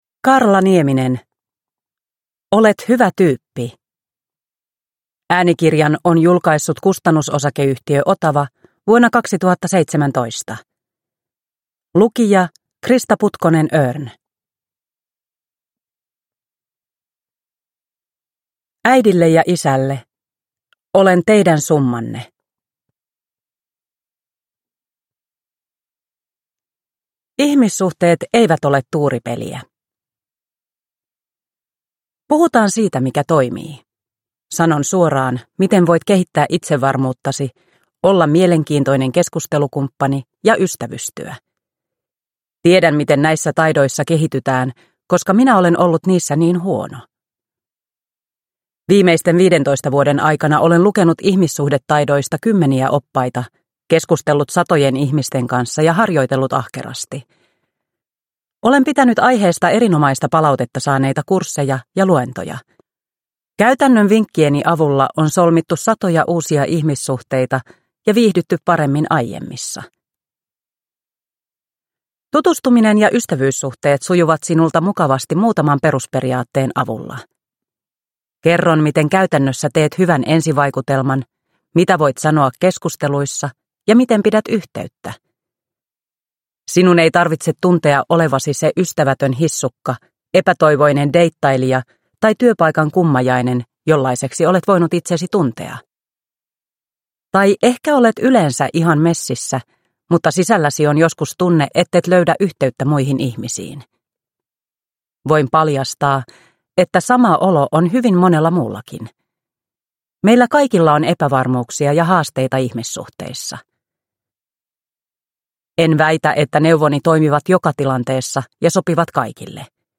Olet hyvä tyyppi – Ljudbok – Laddas ner